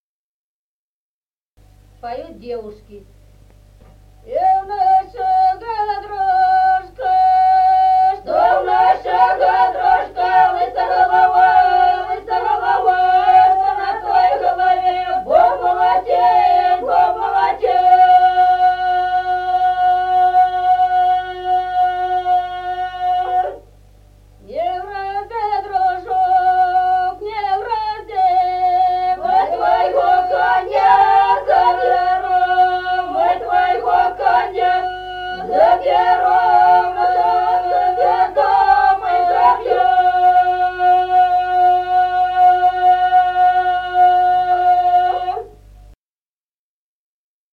Народные песни Стародубского района «И в нашего дружка», свадебная, поют девушки.